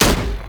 sk08_shot.wav